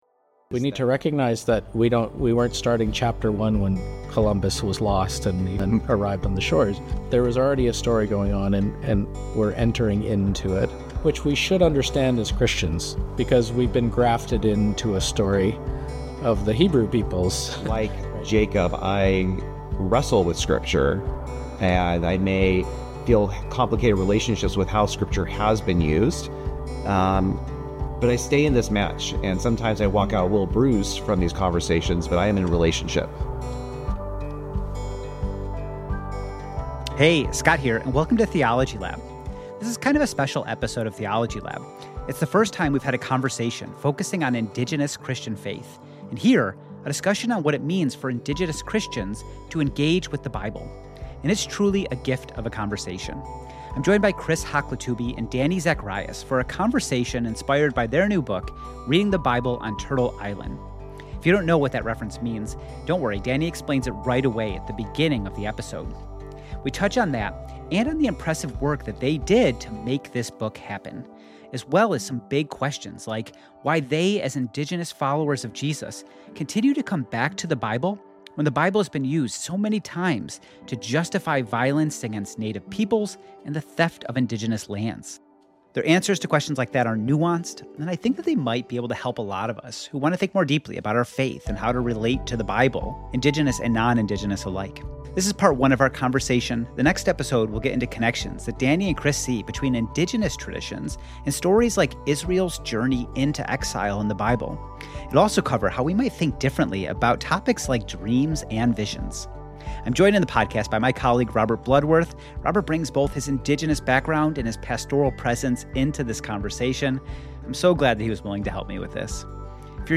This is part 1 of the interview.